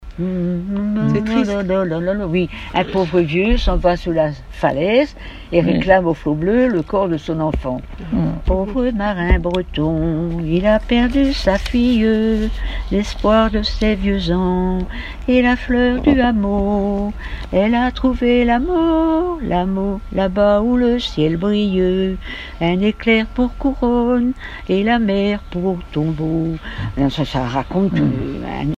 Mémoires et Patrimoines vivants - RaddO est une base de données d'archives iconographiques et sonores.
Genre strophique
Témoignages et chansons
Pièce musicale inédite